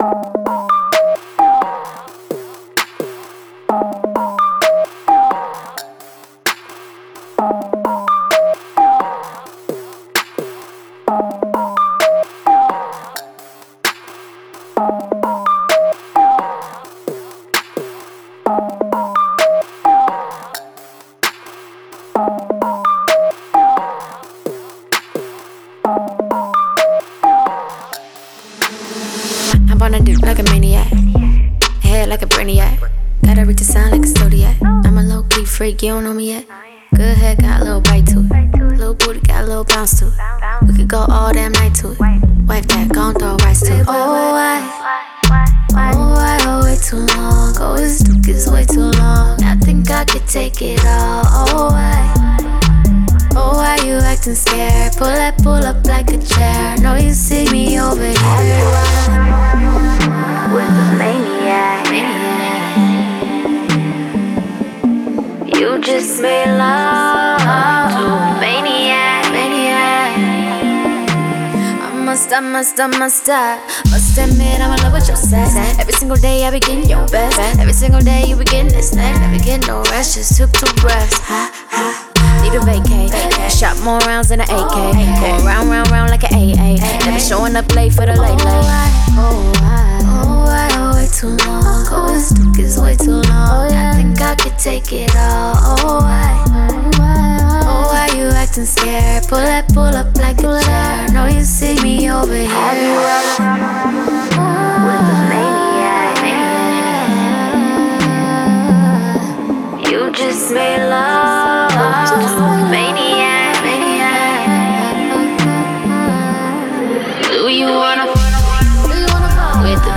SINGLESR&B/SOUL